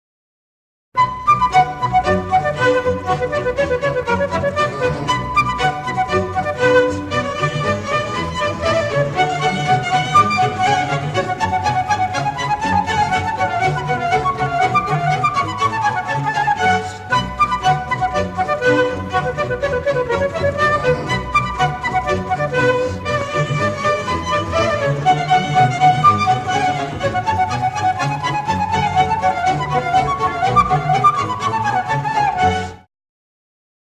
Categories Classical Ringtones